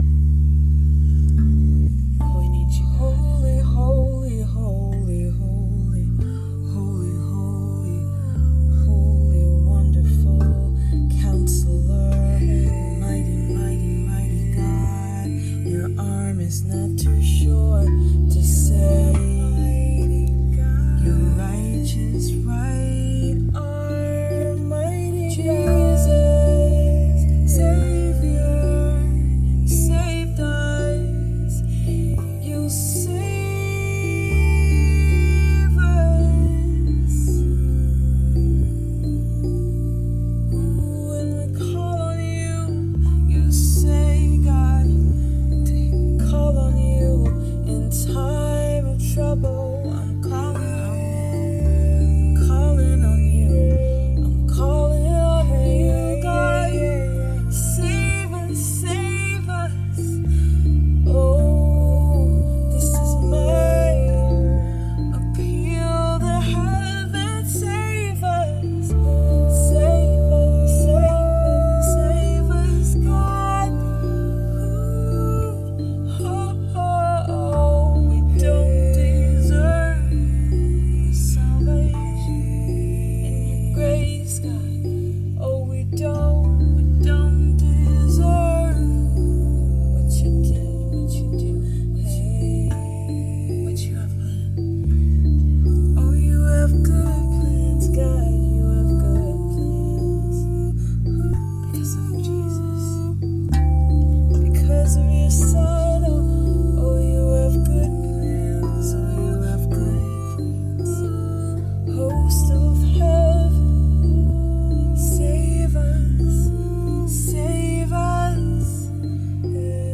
An Appeal To Heaven, SOS, Rav Vast & Beats Sessions 11-6-24